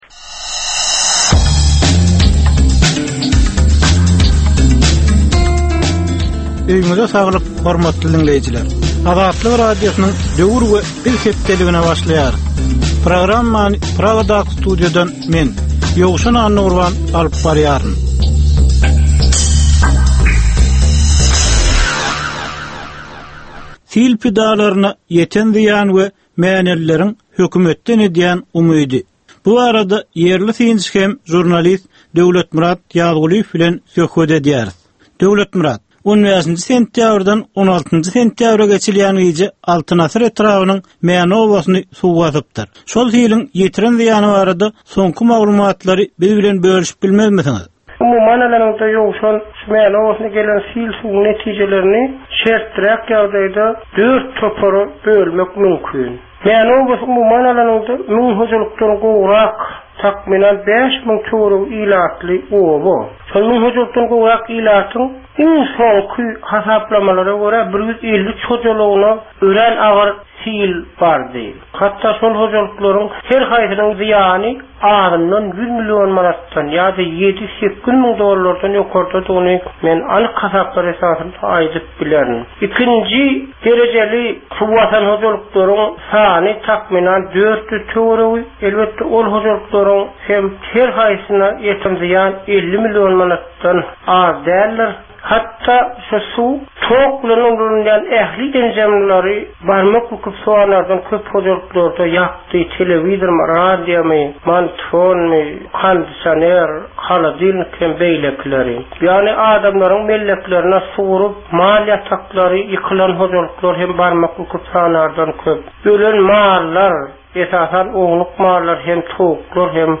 Türkmen jemgyýetindäki döwrüň meseleleri. Döwrüň anyk bir meselesi barada 10 minutlyk ýörite syn-gepleşik. Bu gepleşikde diňleýjiler, synçylar we bilermenler döwrüň anyk bir meselesi barada pikir öwürýärler, öz garaýyşlaryny we tekliplerini orta atýarlar.